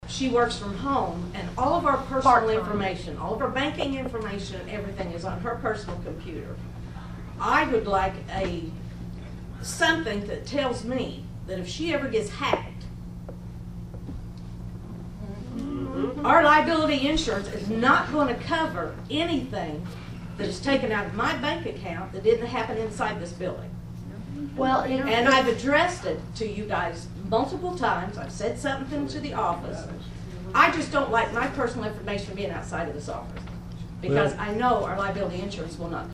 Over the next hour, only one individual abided by that request and it was mostly a group conversation that resembled Great Britain’s parliamentary procedures with interruptions and occasional raised voices.